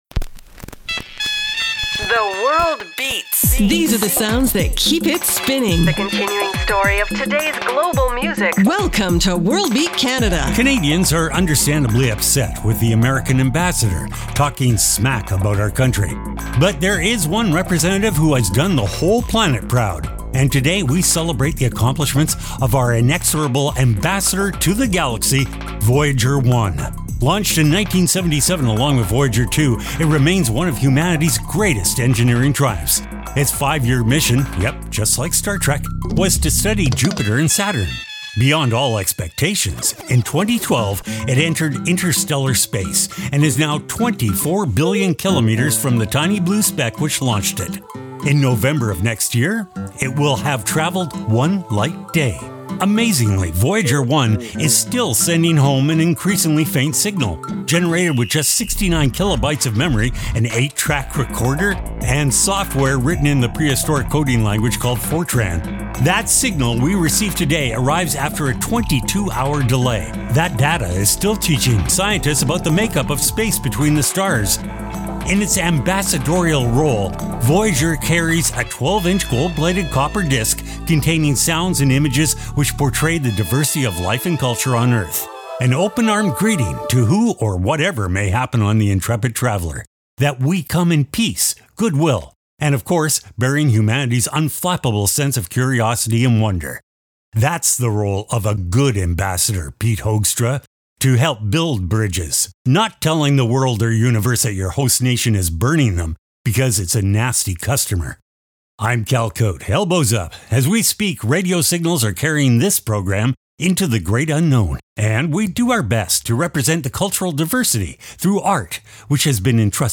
exciting global music alternative to jukebox radio
Weekly Program